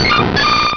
pokeemmo / sound / direct_sound_samples / cries / blissey.wav